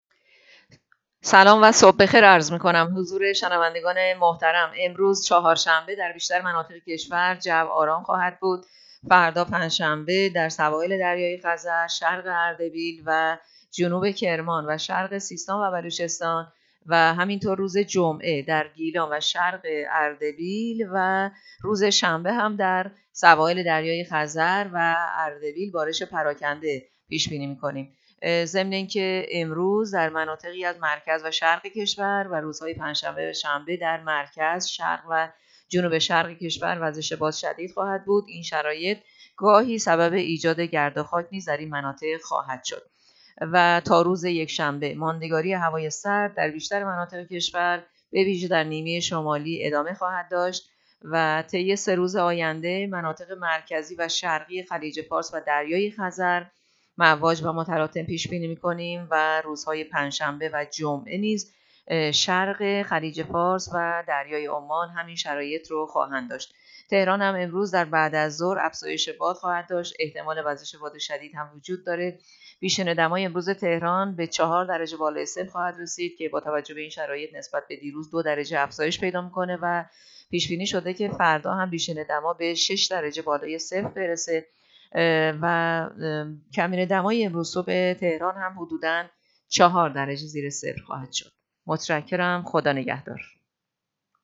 گزارش رادیو اینترنتی پایگاه‌ خبری از آخرین وضعیت آب‌وهوای ۸ اسفند؛